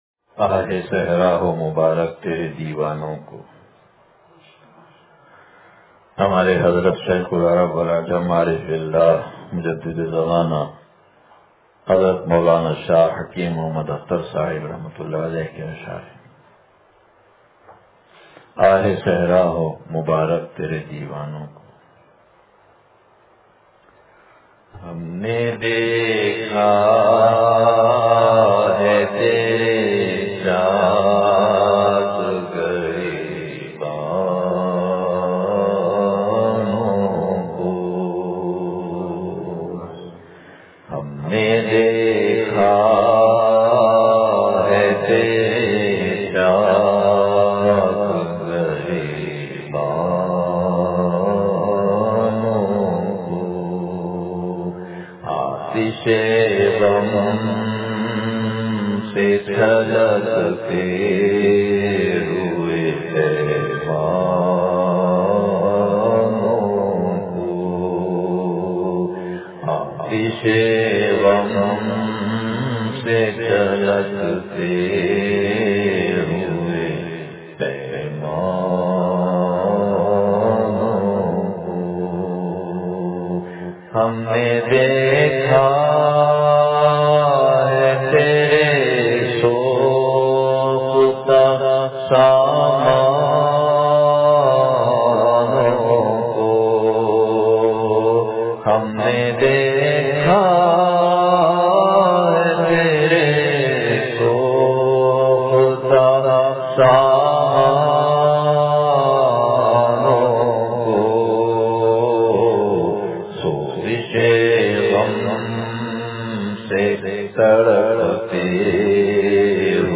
آہِ صحرا ہو مبارک ترے دیوانوں کو – مجلس بروز اتوار